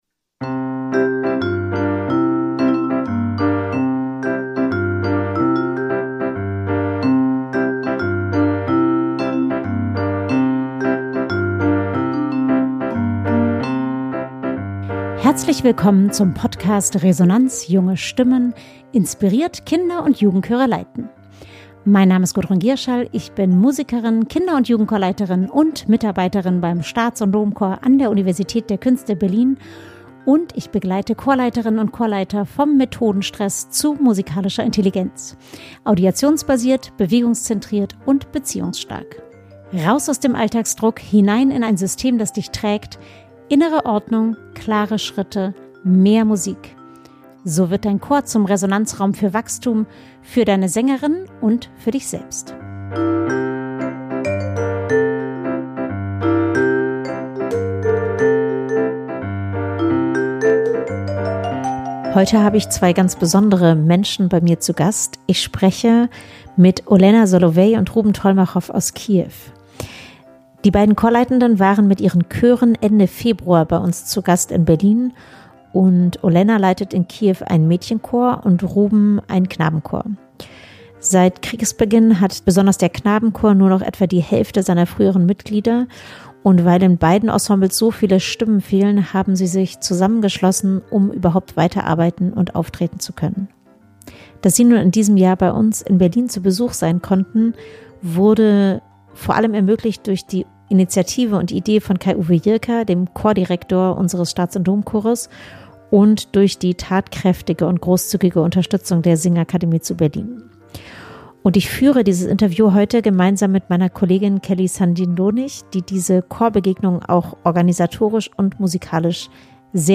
Ein Gespräch über Chorarbeit im Krieg